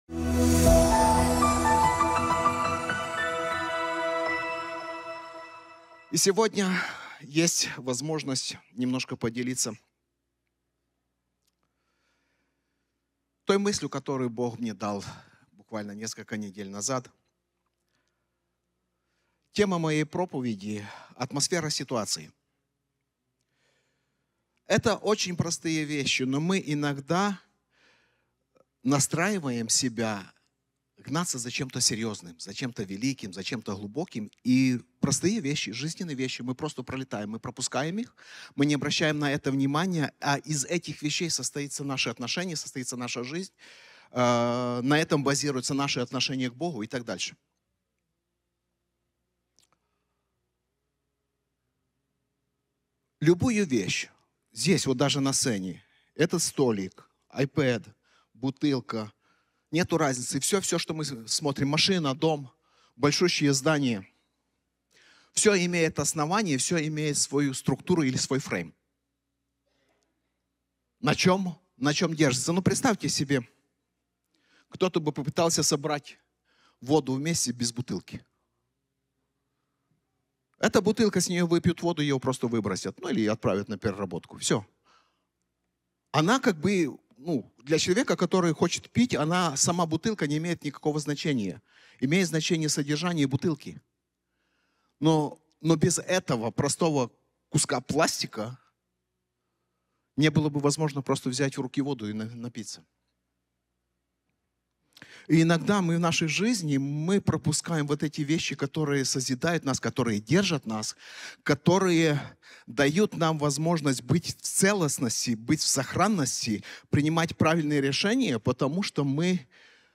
Проповеди и молитвы Центра Трансформации